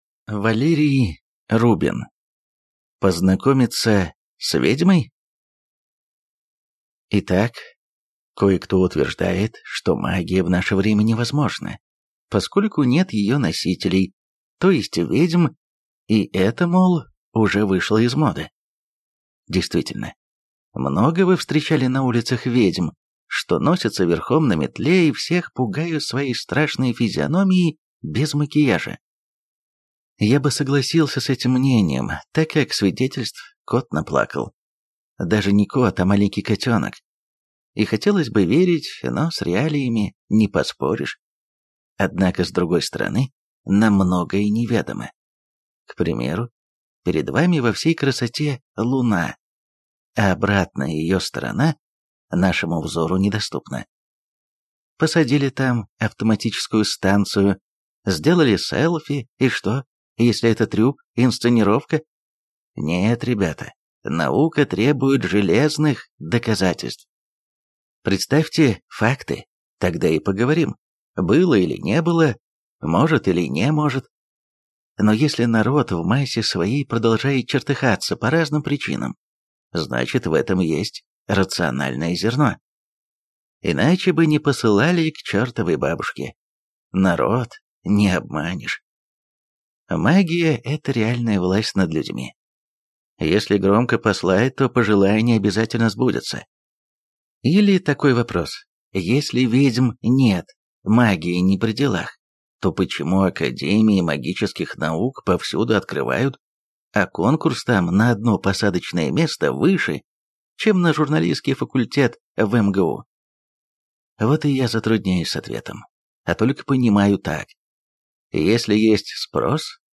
Аудиокнига Познакомиться с ведьмой?